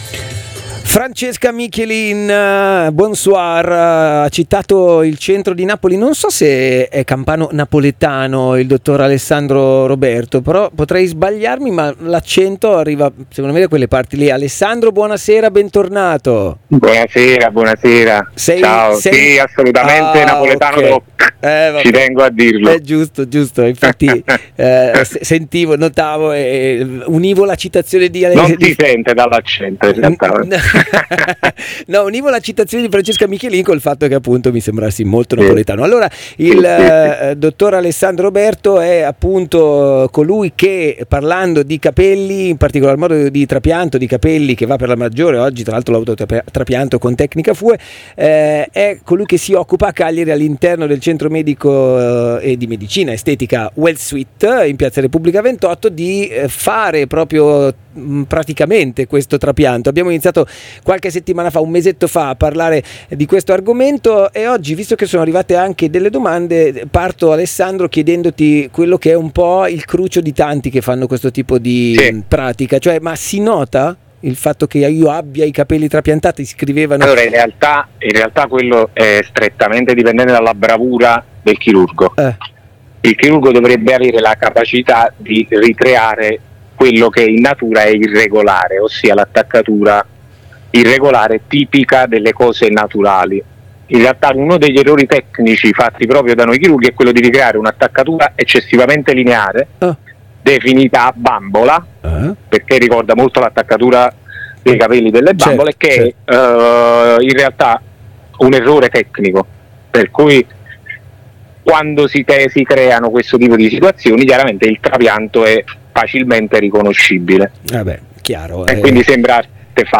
Intervista sul trapianto a Capelli lunghi